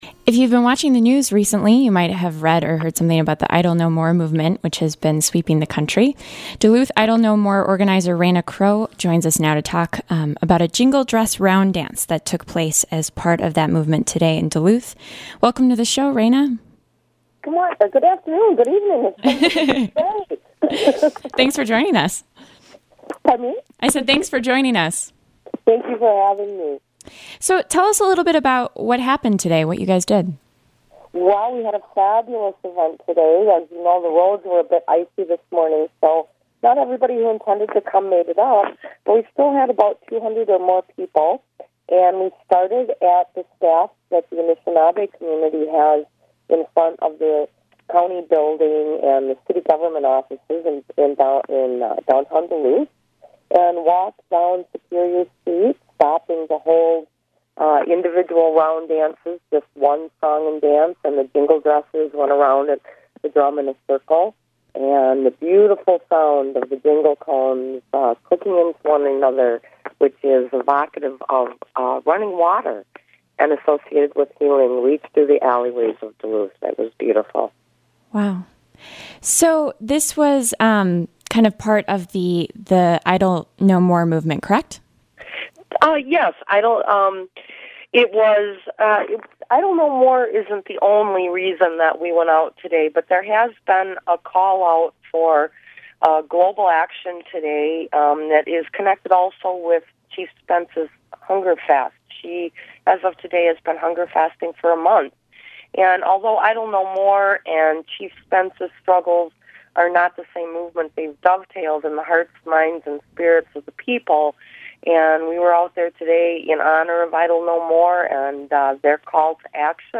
Idle No More movement